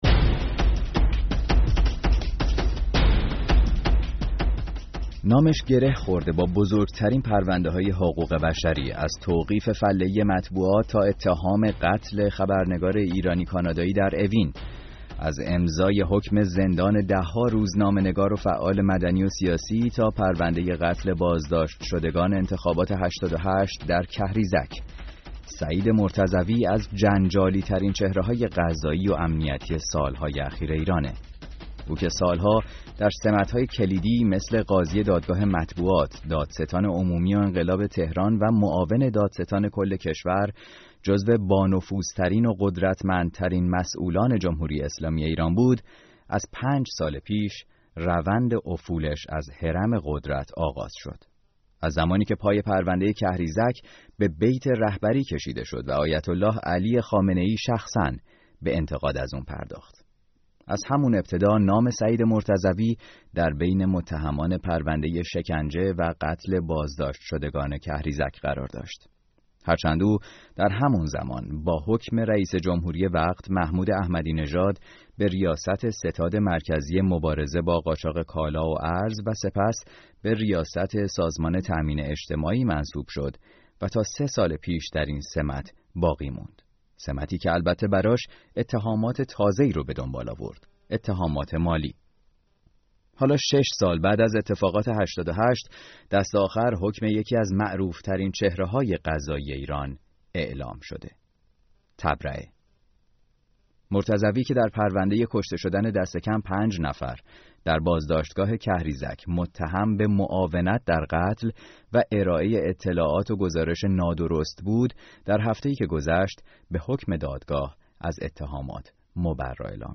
برنامه ساعت ششم این هفته میزبان مهرانگیز کار حقوقدان ساکن آمریکا و مخاطبان رادیو فردا بود تا به فراز و فرودهای پدیده ای به نام «قاضی مرتضوی» بپردازیم.